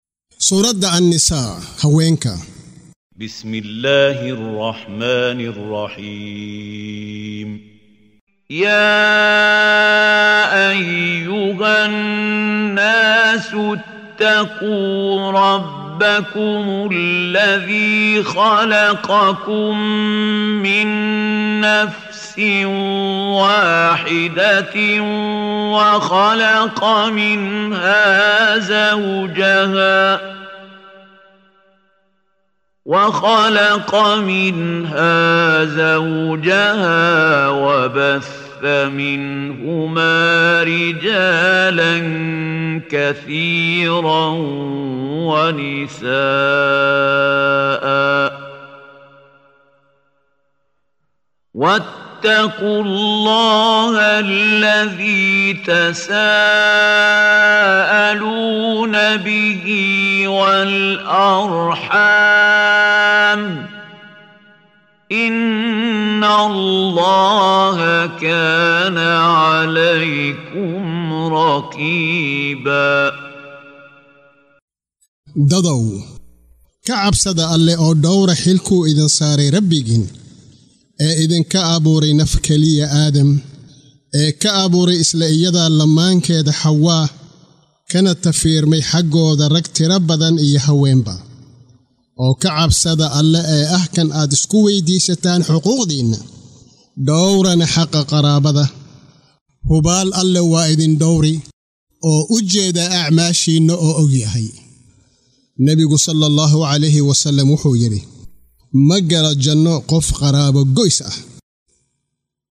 Waa Akhrin Codeed Af Soomaali ah ee Macaanida Suuradda An-Nissaa ( Haweenka ) oo u kala Qaybsan Aayado ahaan ayna la Socoto Akhrinta Qaariga Sheekh Maxmuud Khaliil Al-Xusari.